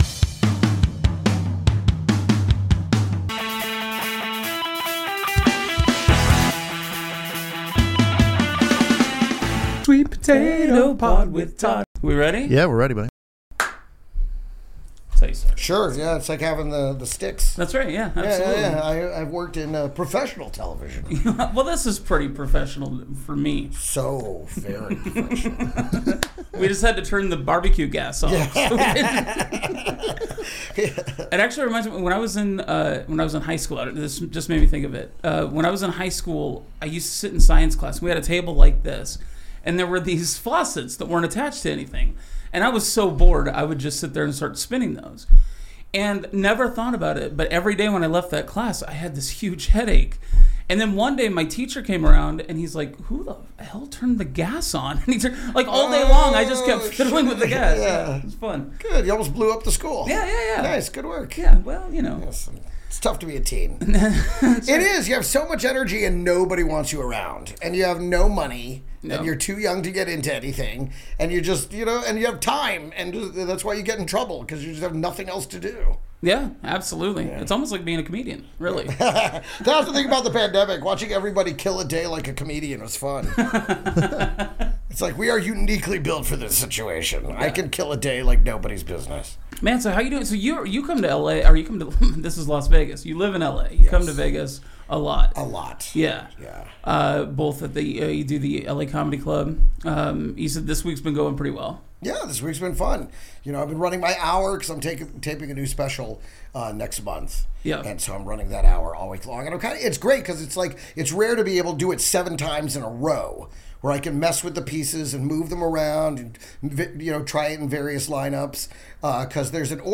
Genres: Comedy , Comedy Interviews , Improv